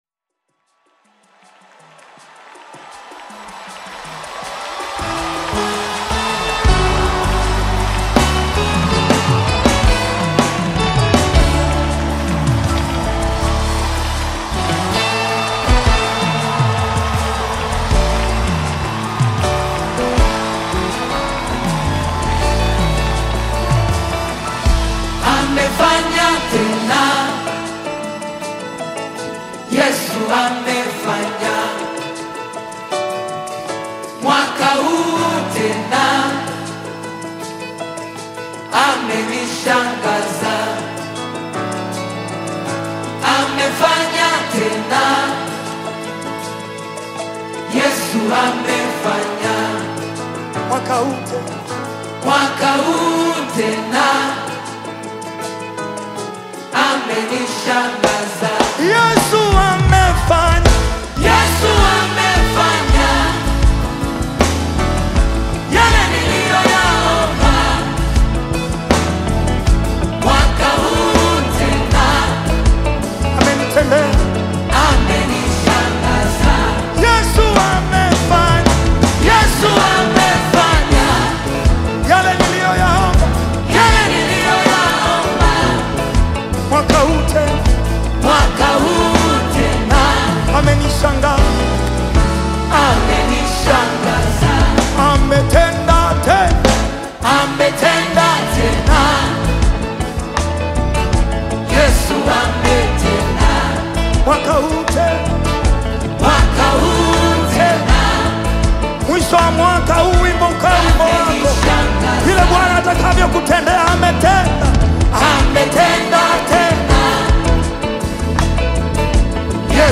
Tanzanian gospel music